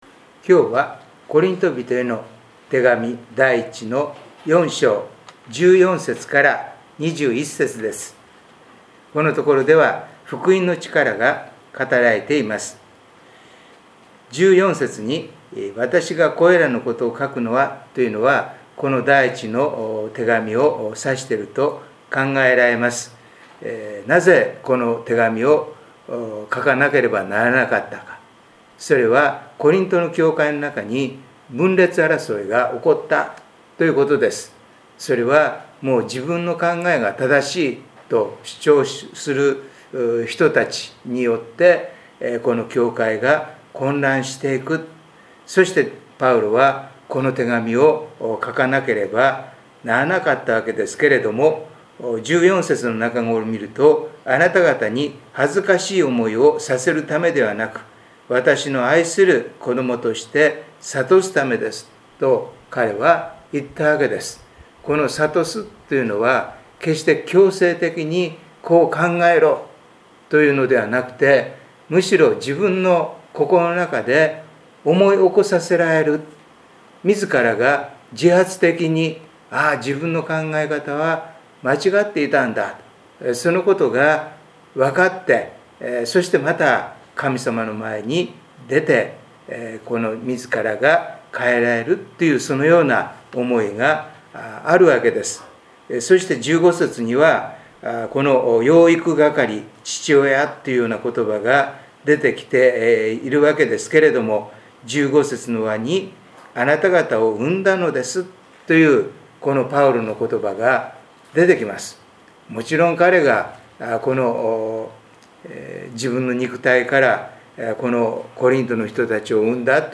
第三主日 主日礼拝